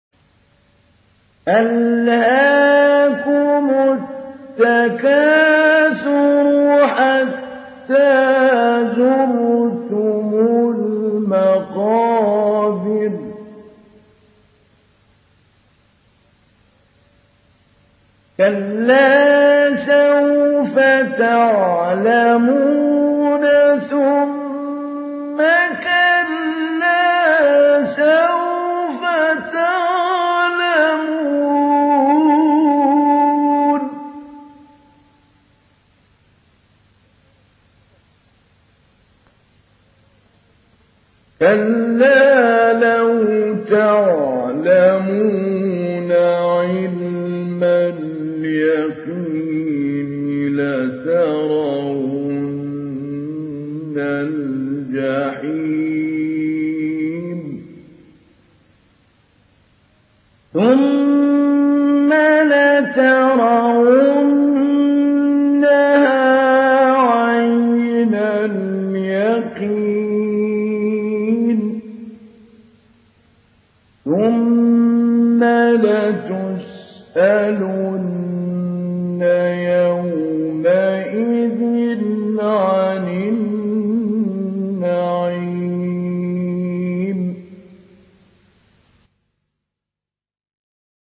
Surah التكاثر MP3 in the Voice of محمود علي البنا مجود in حفص Narration
Listen and download the full recitation in MP3 format via direct and fast links in multiple qualities to your mobile phone.